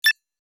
Download Free Sci-Fi Computer Sound Effects | Gfx Sounds
Computer-beeps-digital-menu-single-beep-3.mp3